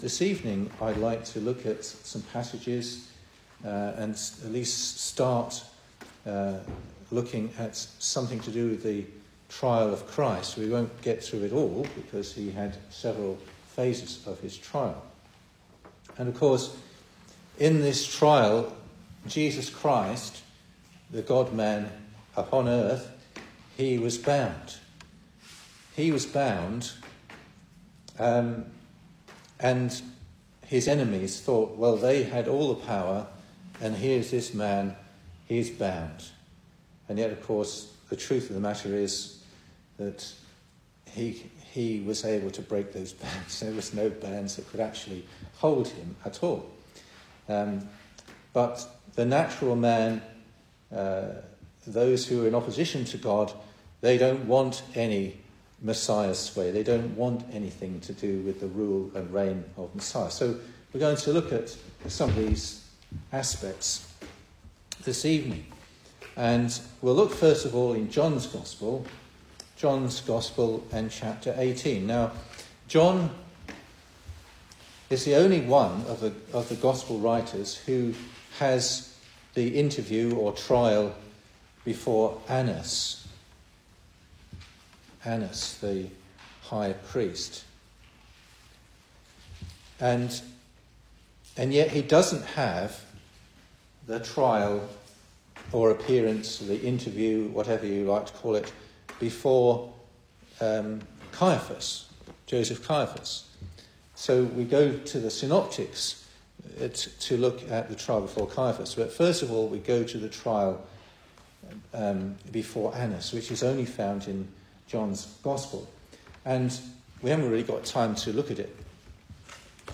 2025 Service Type: Weekday Evening Speaker
Single Sermons